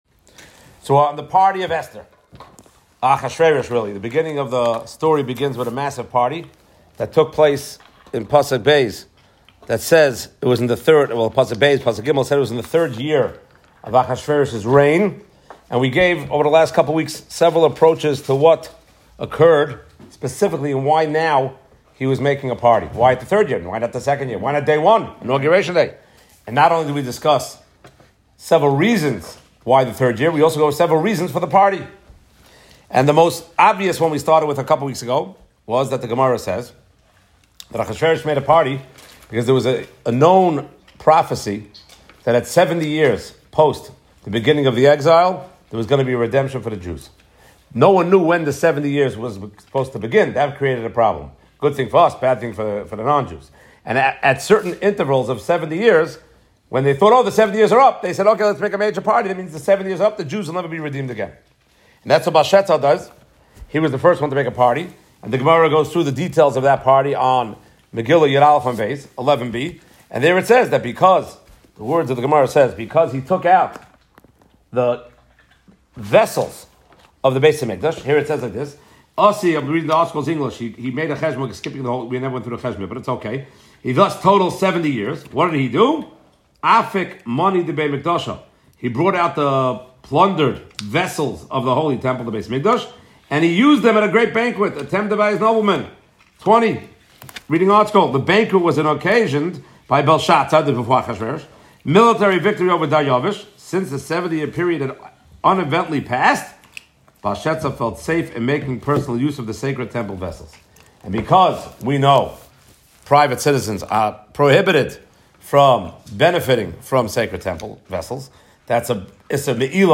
From Young Israel Beth-El, Brooklyn NY